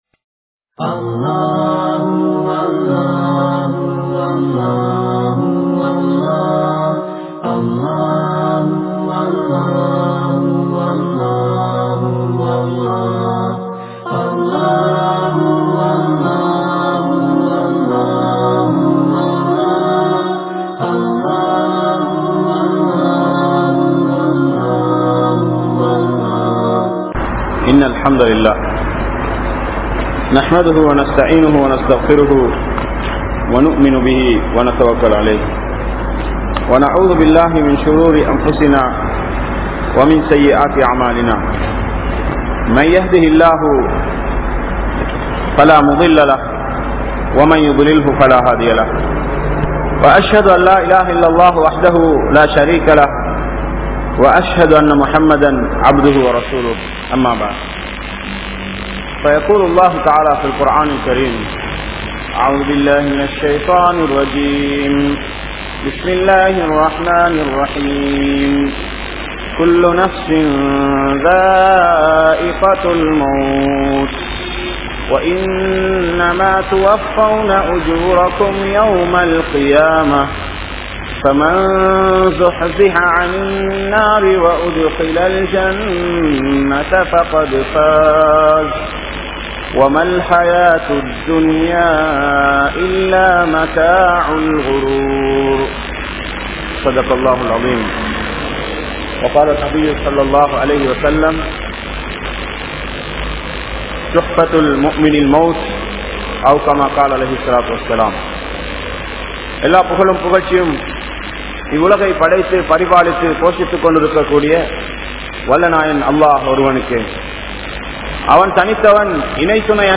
Marumai Vaalkai (மறுமை வாழ்க்கை) | Audio Bayans | All Ceylon Muslim Youth Community | Addalaichenai